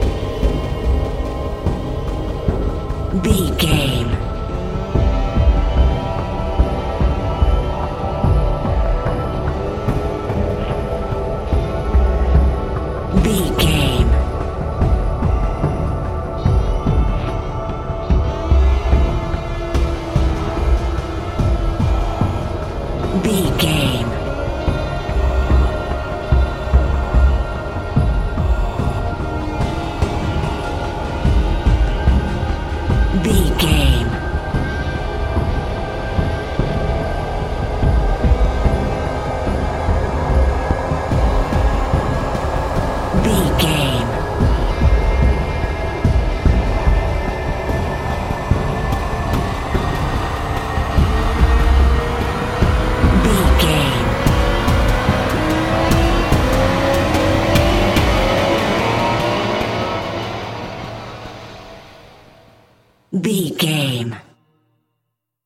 Thriller
Aeolian/Minor
synthesiser
drum machine
ominous
dark
suspense
haunting
tense
spooky